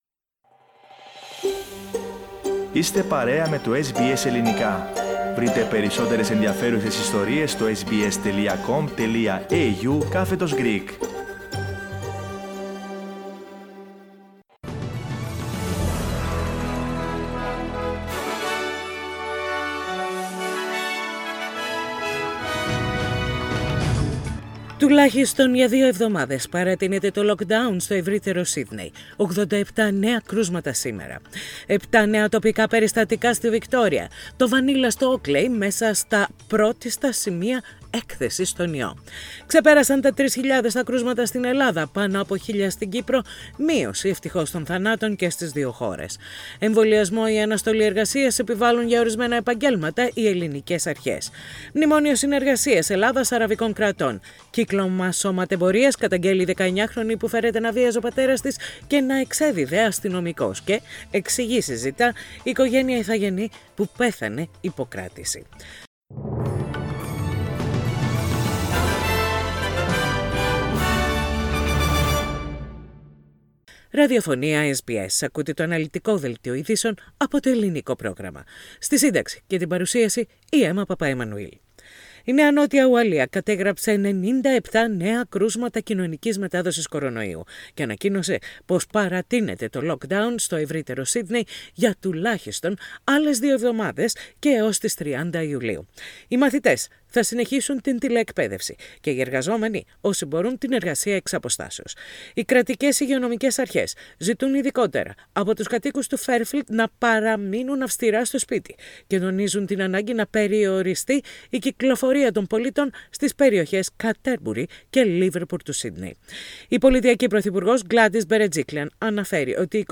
Ειδήσεις στα Ελληνικά - Τετάρτη 14.7.21